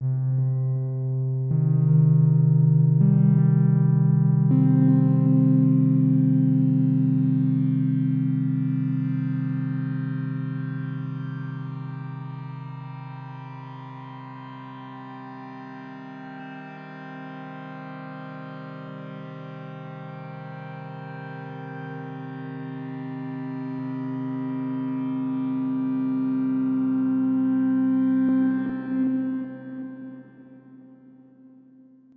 made this short little pingpong loop